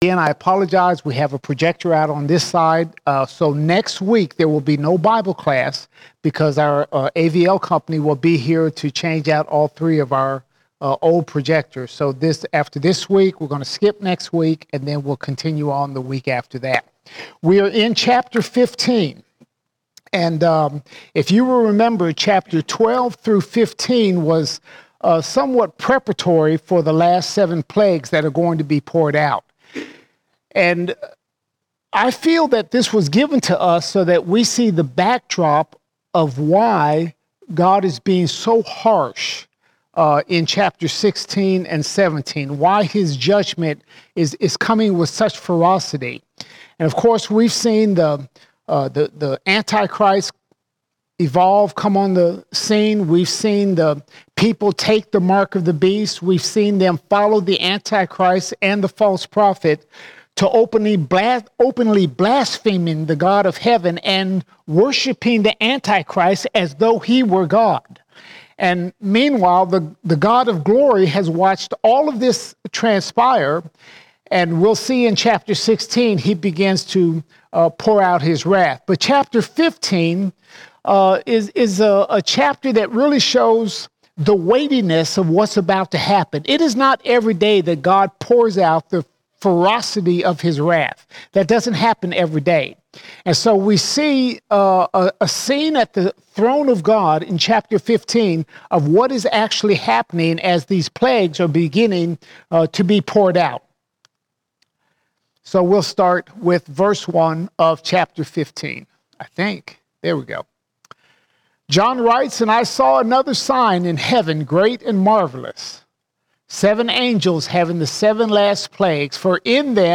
31 October 2024 Series: Revelation All Sermons Revelation 15:1 to 16:20 Revelation 15:1 to 16:20 We see an unrepentant world worship the antichrist.